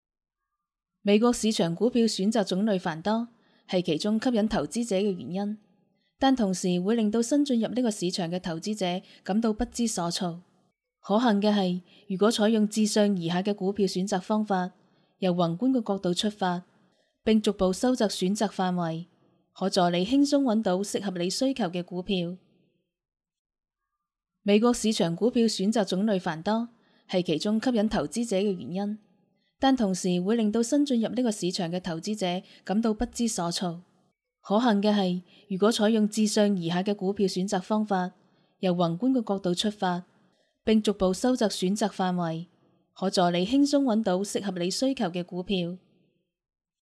女粤1
积极向上|亲切甜美